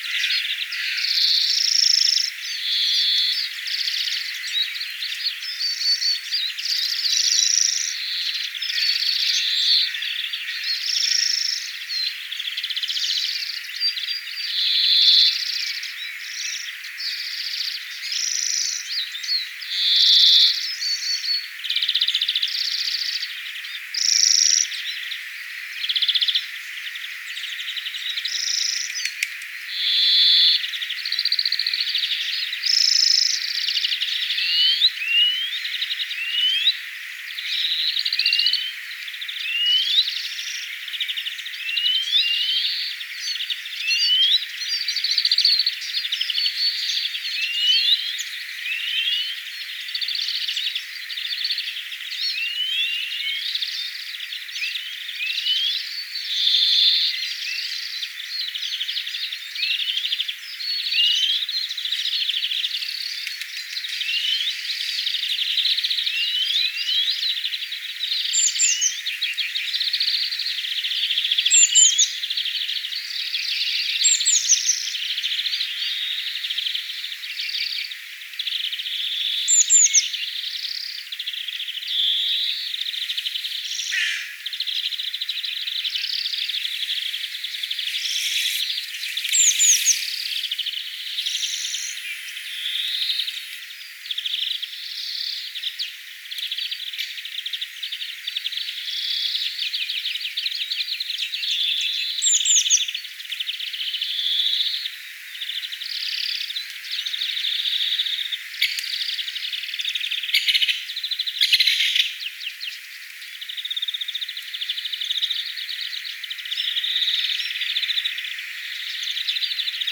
viherpeipot laulavat
viherpeipot_laulavat.mp3